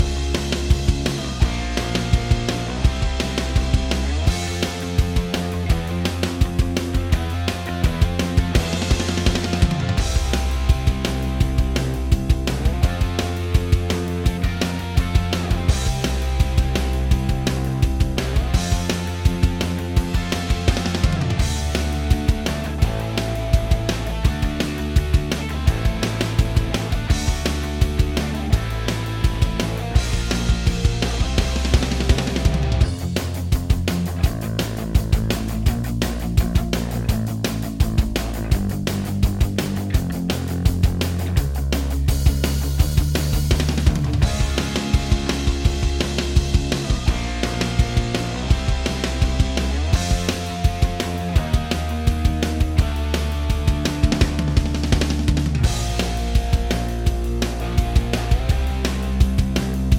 Minus Main Guitars For Guitarists 2:23 Buy £1.50